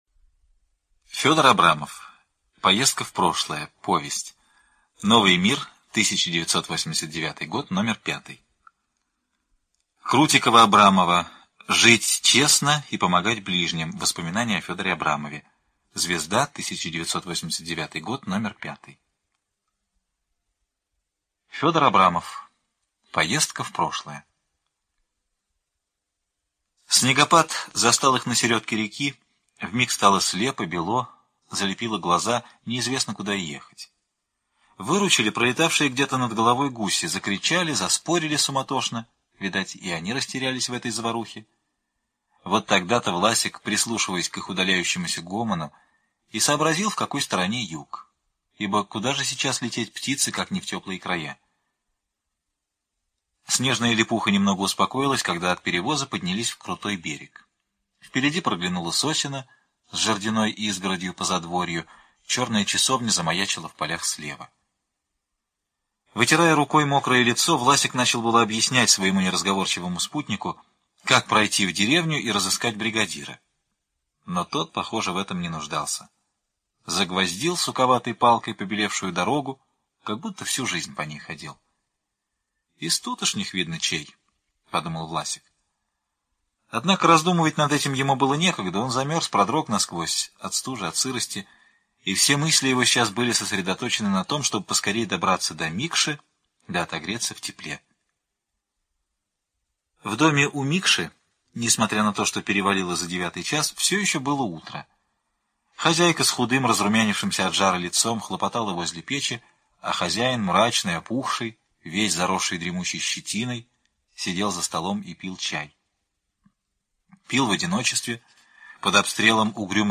На данной странице вы можете слушать онлайн бесплатно и скачать аудиокнигу "Поездка в прошлое" писателя Фёдор Абрамов. Включайте аудиосказку и прослушивайте её на сайте в хорошем качестве.